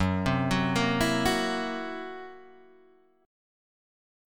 F#7b5 chord